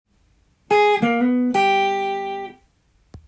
G7を例にフレーズを作ってみました。
②は♭９から間接的に１度に移動するというIQが高い
作ったのではなかろうかと小洒落たフレーズです。